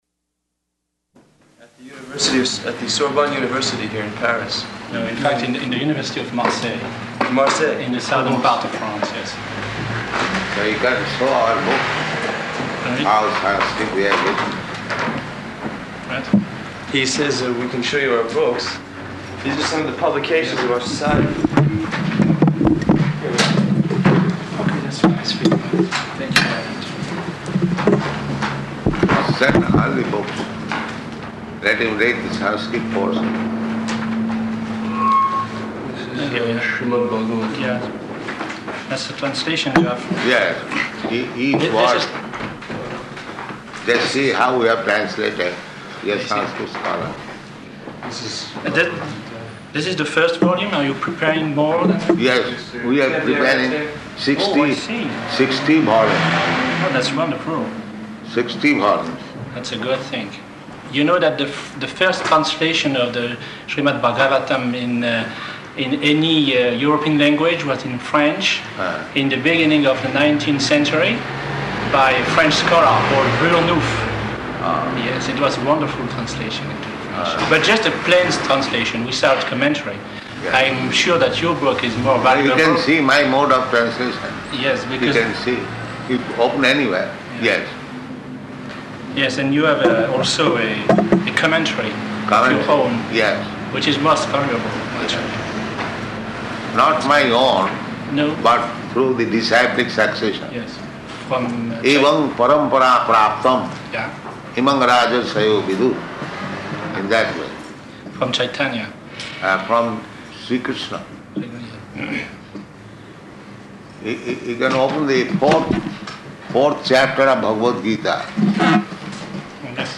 Room Conversation with Sanskrit Professor